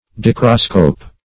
Search Result for " dichroscope" : The Collaborative International Dictionary of English v.0.48: Dichroscope \Di"chro*scope\, n. [Gr. di- = di`s- twice + ? color + ? to view.] An instrument for examining the dichroism of crystals.